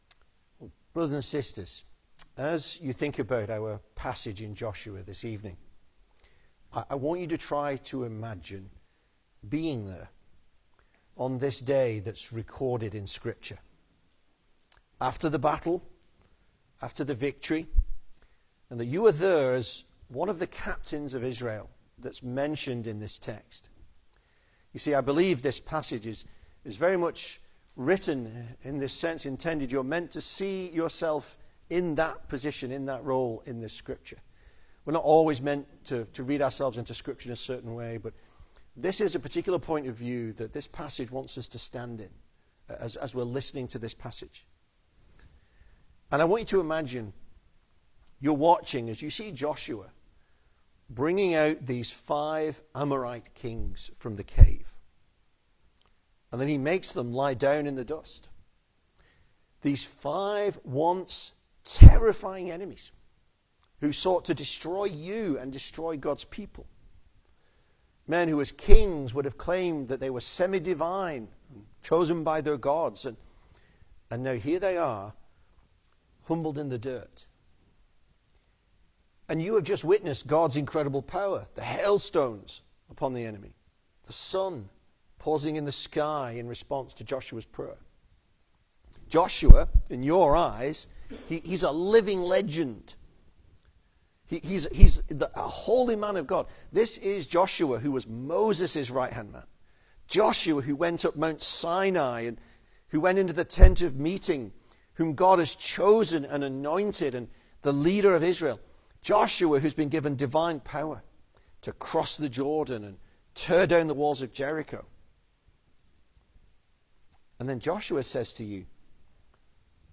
2026 Service Type: Sunday Evening Speaker